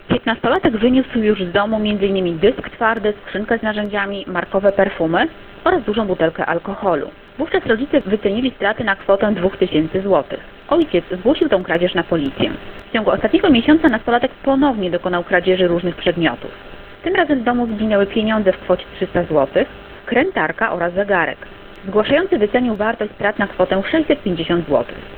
– Nastolatek na tym nie poprzestał – mówi